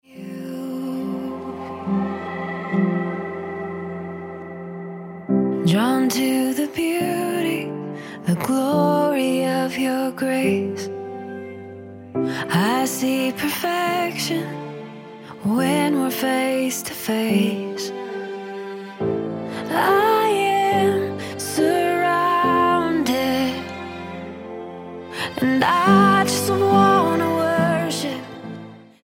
STYLE: Pop
piano-driven ballad